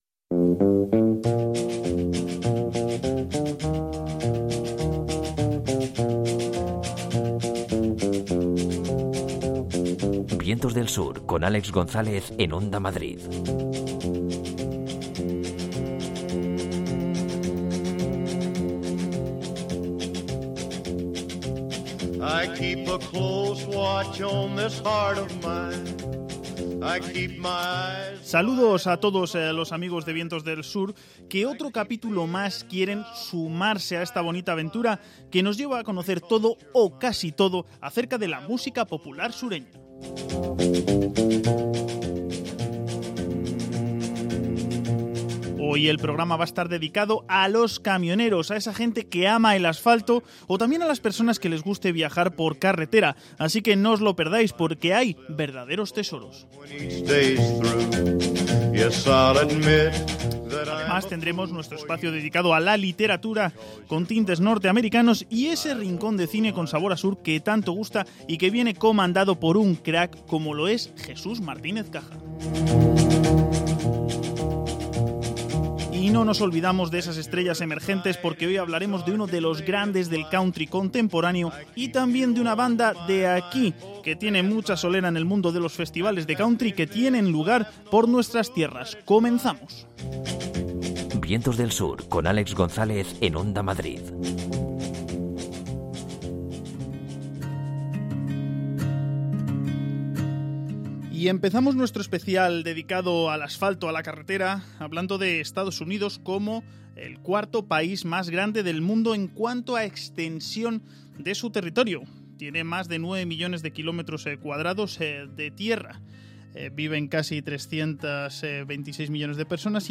rinde homenaje a los amantes del asfalto con grandes clásicos del country que hablan acerca de la carretera.